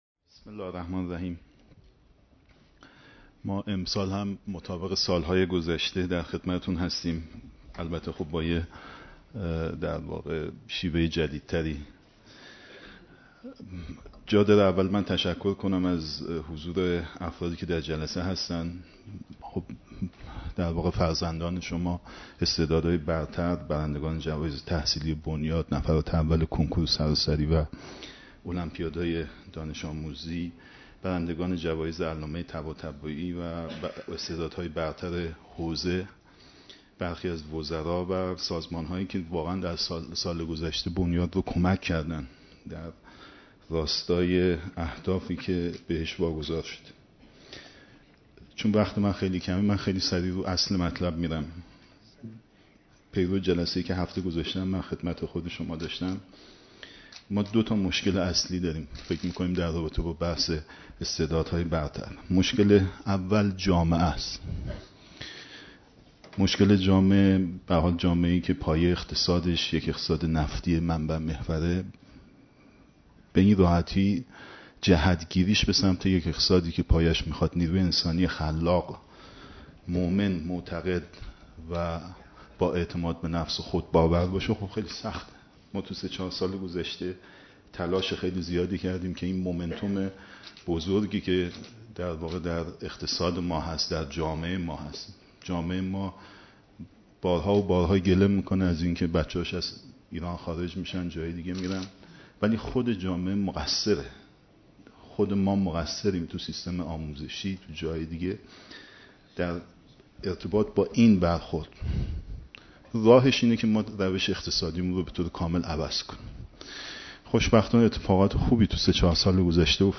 دیدار جمعی از استعدادهای برتر و نخبگان جوان کشور